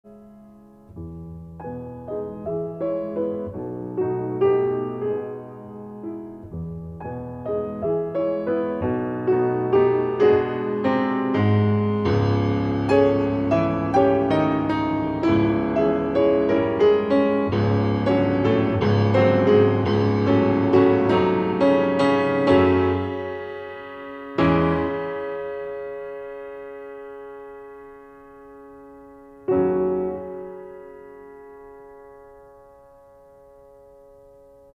鋼琴